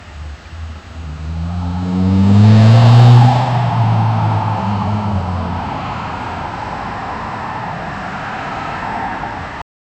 Sudden car braking sound from outside, loud screech of tires on asphalt, sharp skid noise, echo in the empty countryside night, realistic and impactful, no voices, no music, 3 seconds
sudden-car-braking-sound--zj4cbvv4.wav